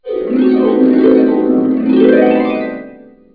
00158_Sound_boladecristal.mp3